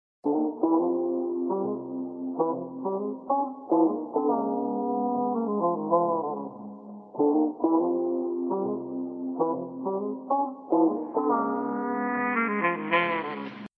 Detective tune Meme Effect sound effects free download